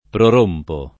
prorompere [pror1mpere] v.; prorompo [
pror1mpo] — coniug. come rompere; assai rari però il part. pass. prorotto [